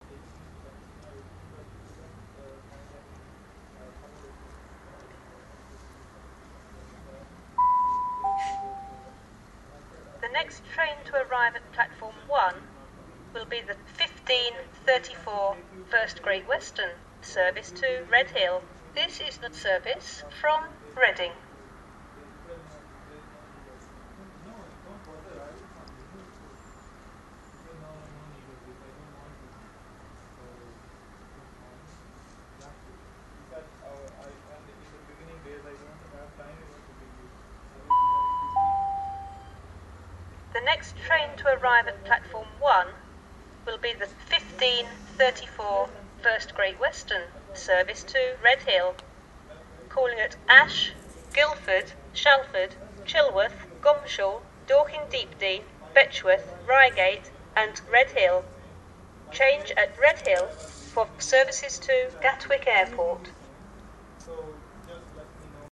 North Camp Railway Station PA Announcements
address announcements automated bell bing bong camp chime sound effect free sound royalty free Memes